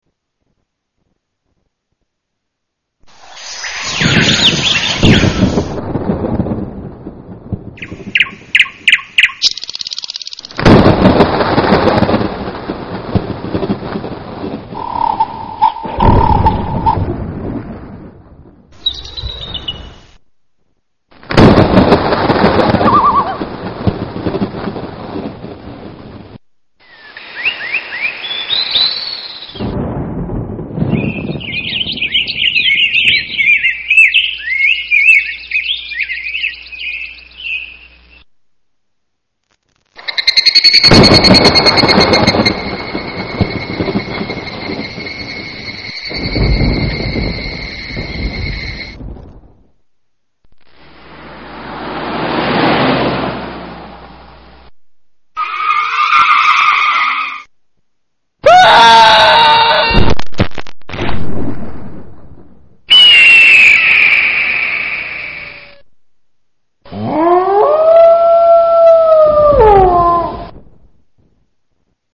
scream-in-night.mp3